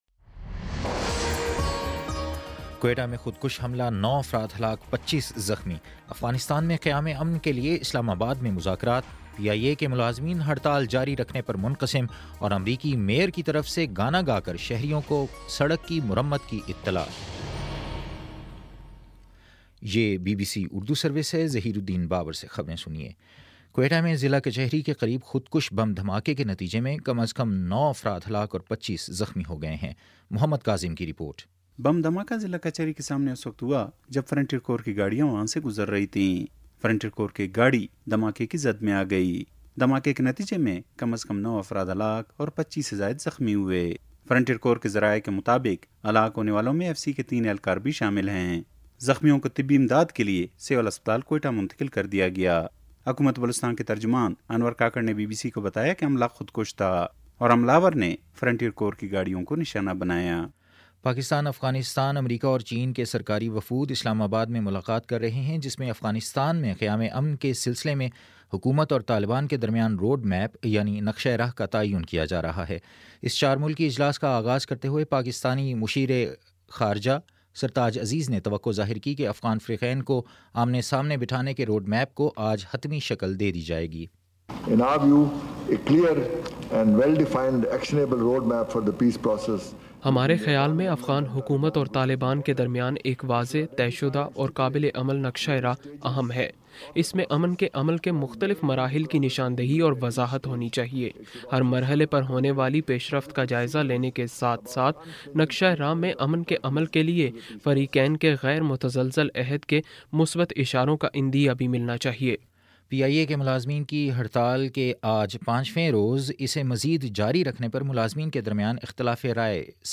فروری 06 : شام چھ بجے کا نیوز بُلیٹن